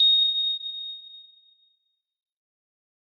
На этой странице собраны звуки слез — нежные, меланхоличные аудиозаписи падающих капель.
Здесь нет записей с плачущими людьми, только чистые звуки слезинок в высоком качестве.
Звук падающей слезы (театральный эффект)